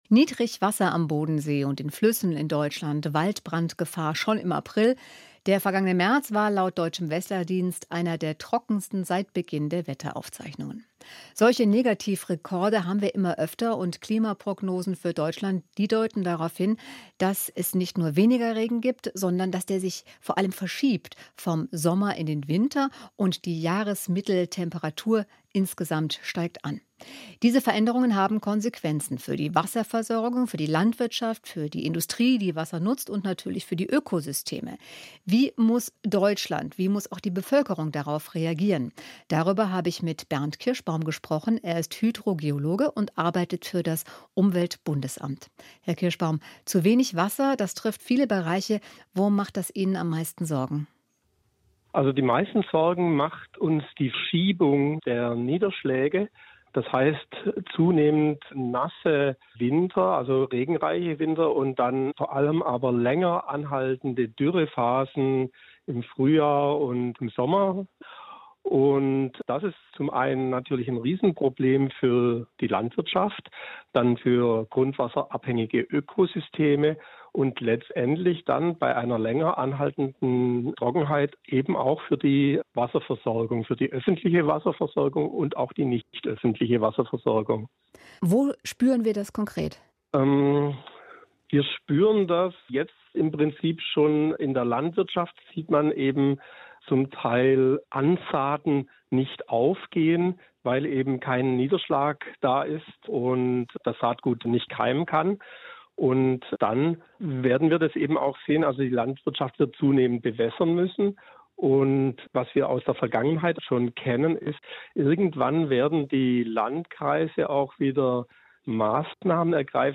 Das hat Konsequenzen für die Wasserversorgung, Landwirtschaft, Industrie und die Ökosysteme. Wie muss Deutschland darauf reagieren? UBA-Experte
im Interview